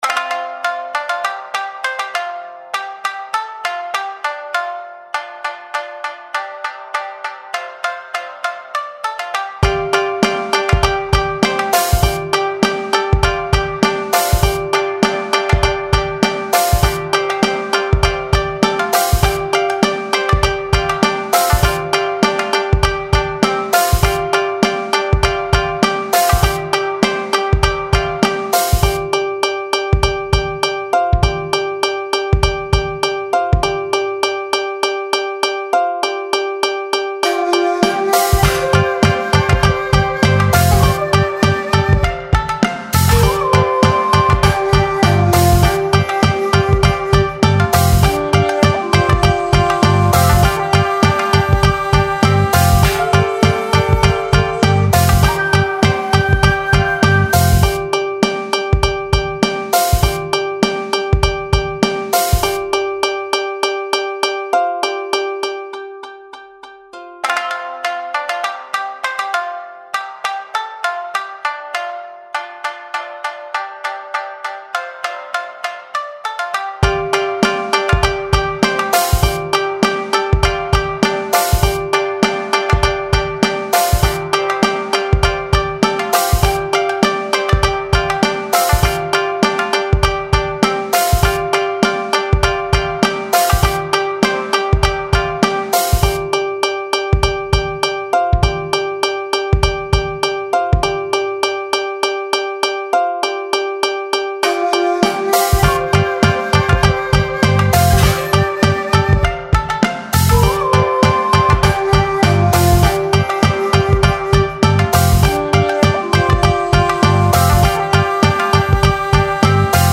BGM
ロング民族穏やか